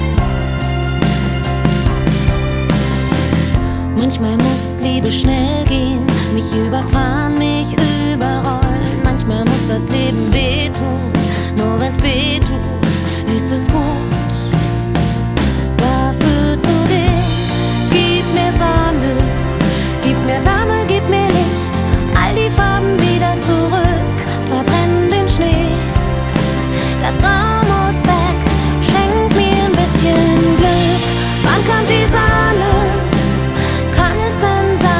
Pop Pop Rock Adult Contemporary Vocal Vocal Pop Britpop
Жанр: Поп музыка / Рок